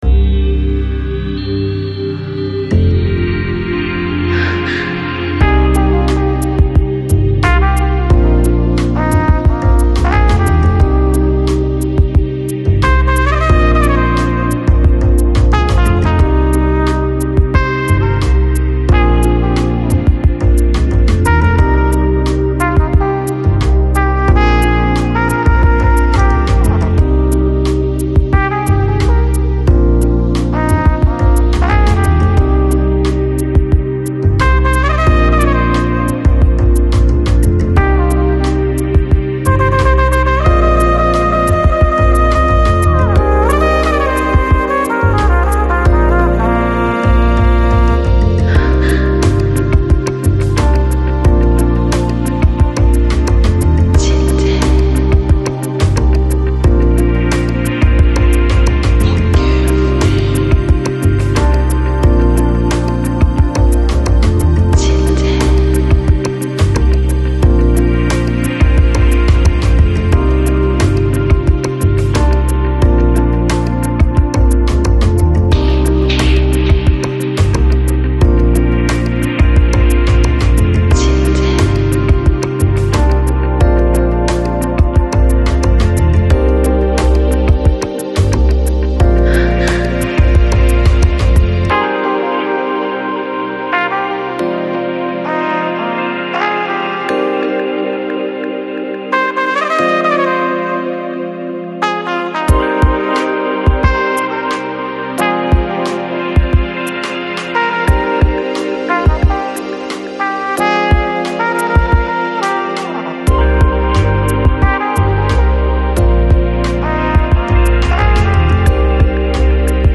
Lounge, Chill Out, Downtempo Год издания